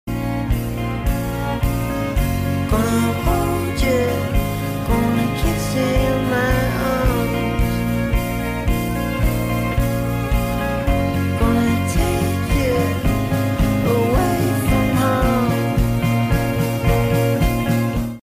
Dramatically sliding down the wall crying